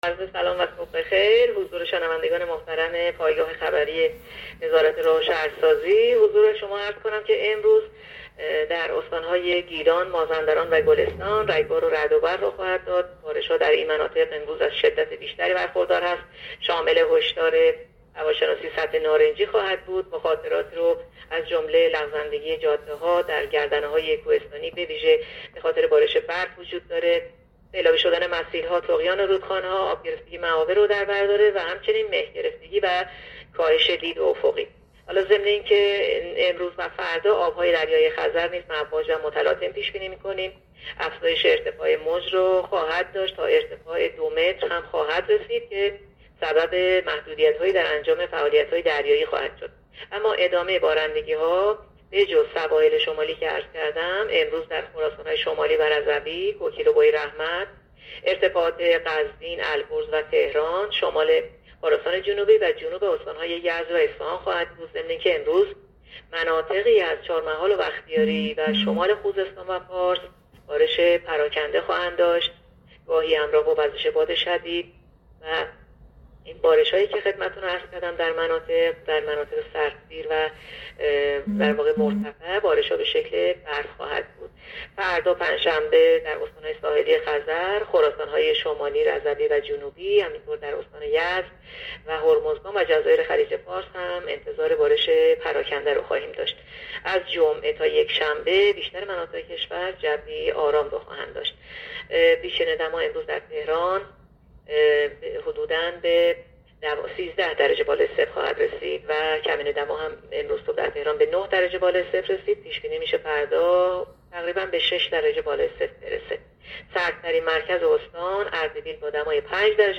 گزارش رادیو اینترنتی پایگاه‌ خبری از آخرین وضعیت آب‌وهوای ۱۲ آذر؛